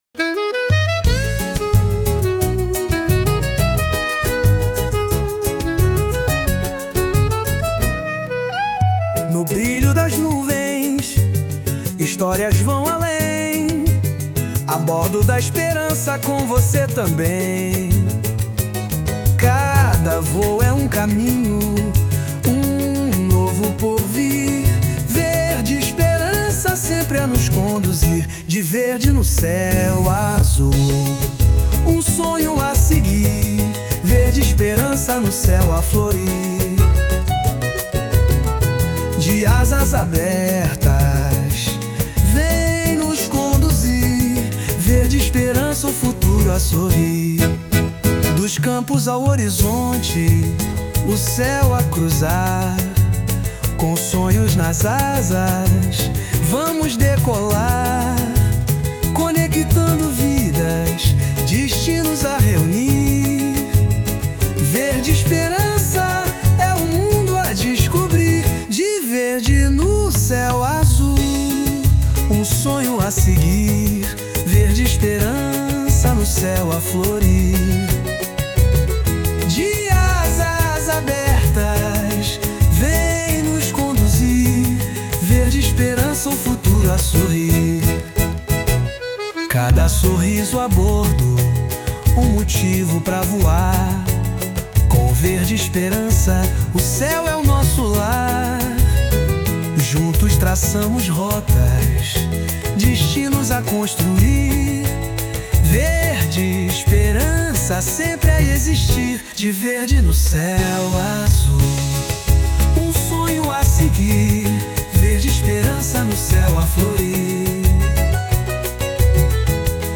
Para celebrar esta data especial, a companhia lança sua nova música de marketing, intitulada "De Verde no Céu Azul", um Samba vibrante que traduz a essência da viagem, da esperança e da união.
e o arranjo musical e vocal foram produzidos com auxílio da inteligência artificial, demonstrando a harmonia entre criatividade humana e inovação tecnológica.
Com seu ritmo envolvente de Samba e uma mensagem vibrante, a Verde Esperança reafirma sua missão de levar sonhos mais alto.
De verde no céu azul (Samba).mp3